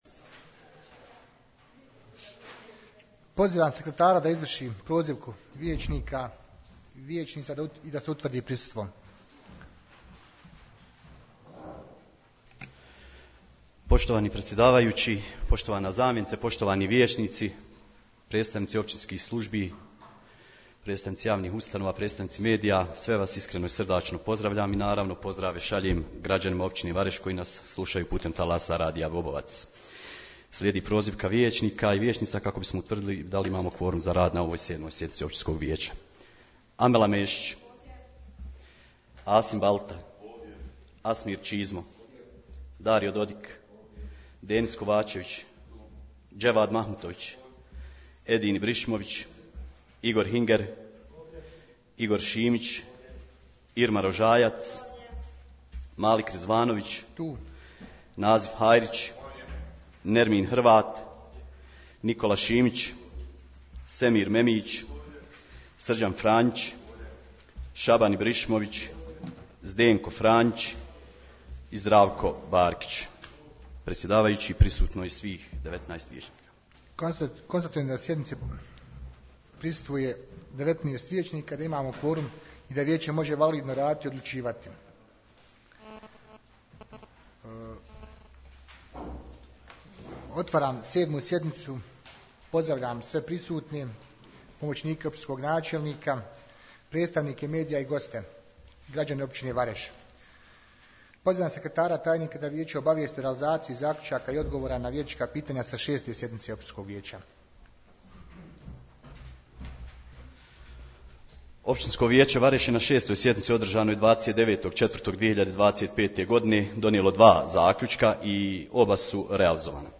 7. sjednica Općinskog vijeća Vareš održana je 29.05.2025. godine, na dnevnom redu bilo je petnaest točaka, poslušajte tonski zapis.....